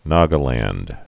(nägə-lănd)